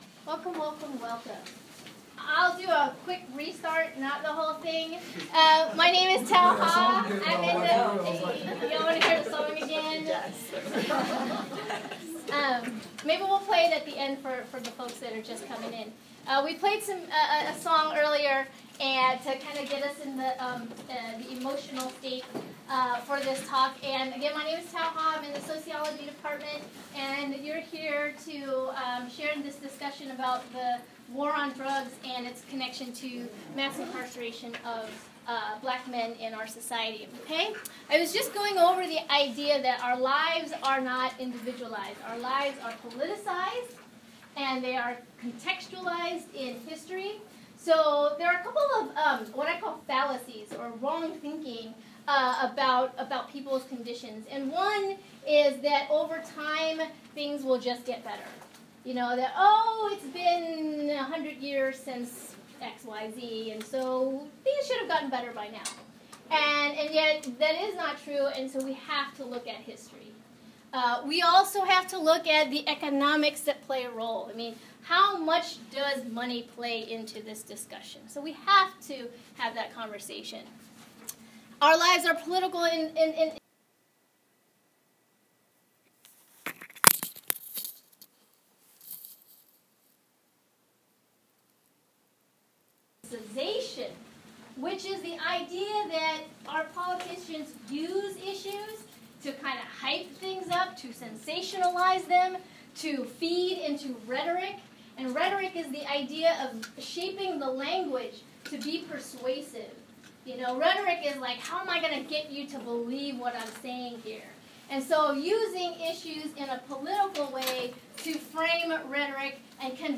Today I gave a talk about Mass Incarceration as the New Form of Jim Crow to kick off Black History Month at MiraCosta College. It was well attended, standing room only.
This is the audio recording (due to my error there is a glitch at 1:20 for about 10 seconds, sorry!):
new-jim-crow-talk-feb-2-2016.m4a